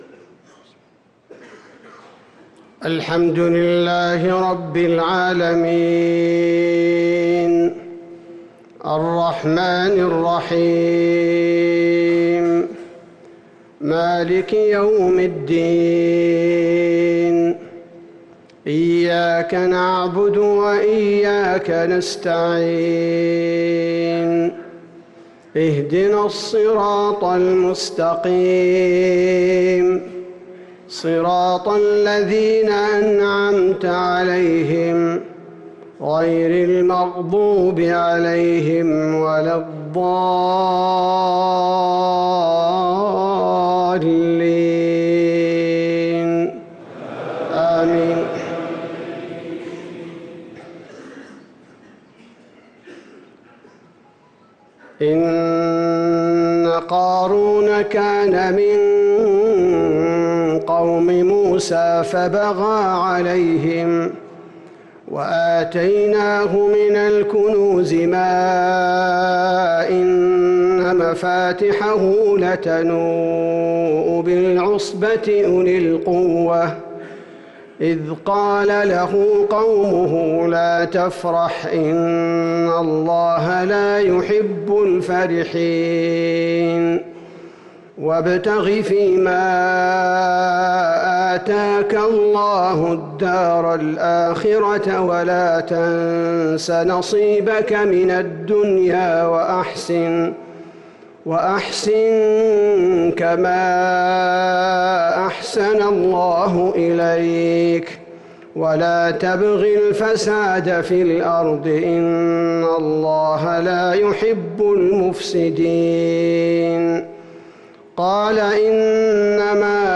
صلاة العشاء للقارئ عبدالباري الثبيتي 17 جمادي الأول 1445 هـ
تِلَاوَات الْحَرَمَيْن .